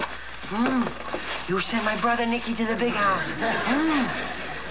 Radar's impression of